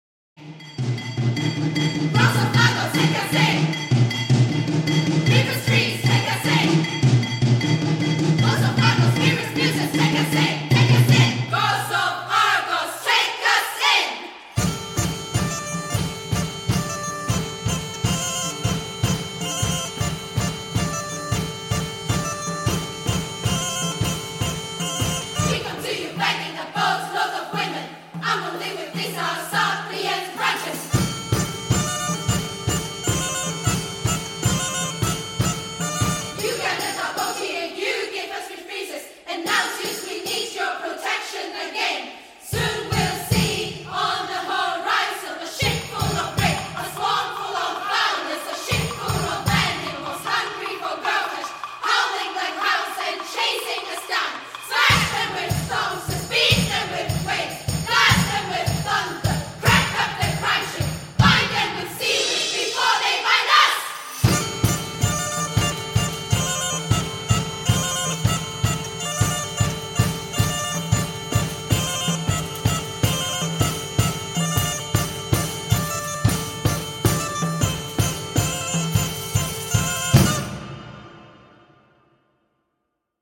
These are sample recordings from the 2016 Production of the play done at the Lyceum Theater in Edinburgh, Scotland.